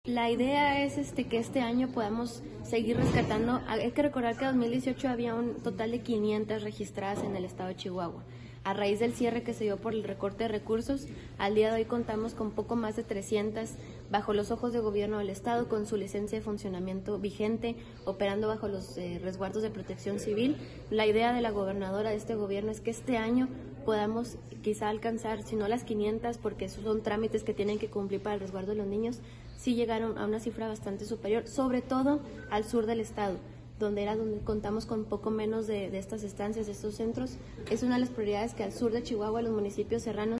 AUDIO: CARLA RIVAS , TITULAR DE LA SECRETARÍA DE DESARROLLO HUMANO Y BIEN COMÚN (SDHyBC)